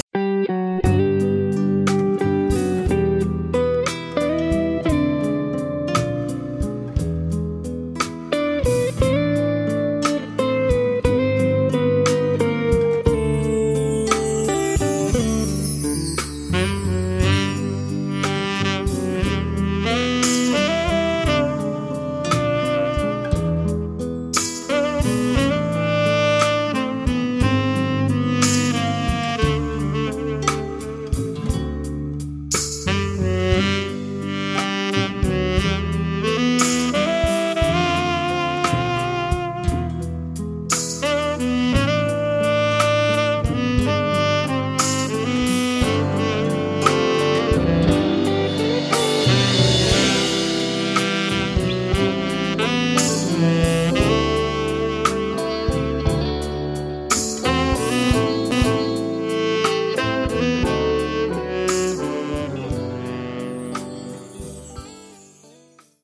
A laid back wholesome piece of jazz pop.
Instrumental Version.